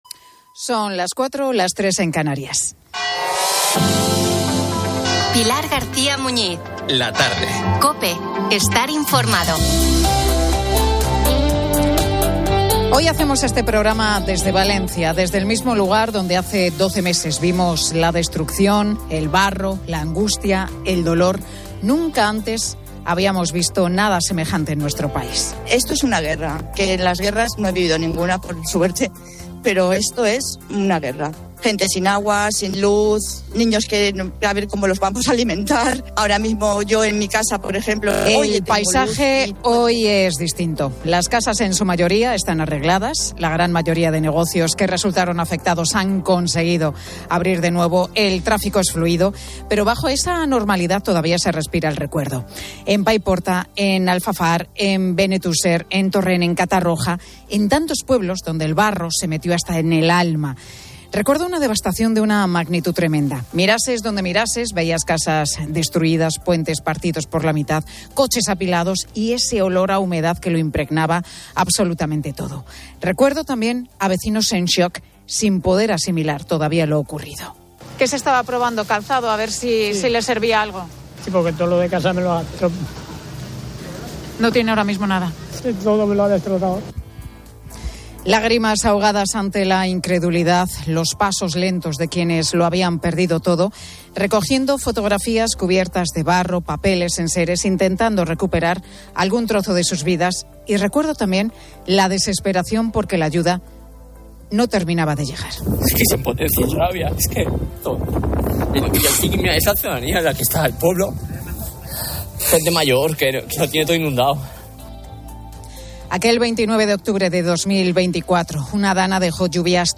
El audio informa desde Valencia, un año después de la Dana que causó destrucción y angustia, comparando la situación con una guerra.